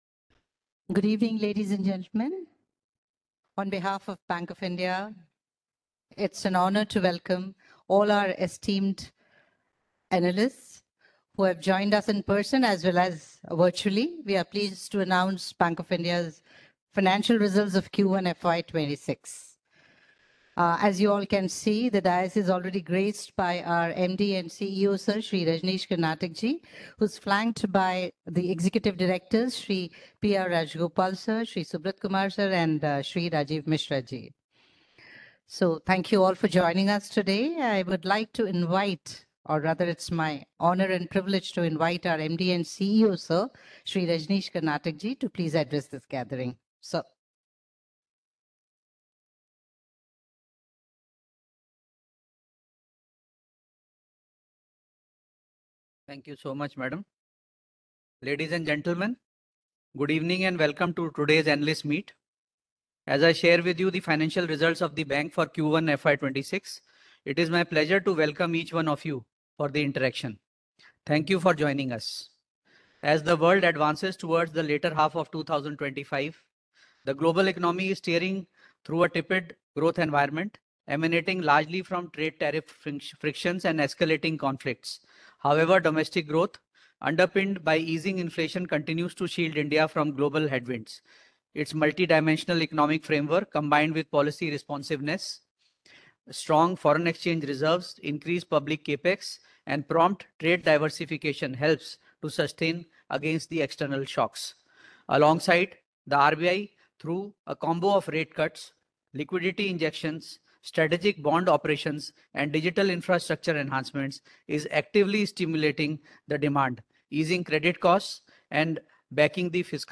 పెట్టుబడిదారుల కాన్ఫరెన్స్ కాల్ రికార్డింగ్ - BOI
Analyst_Meet.mp3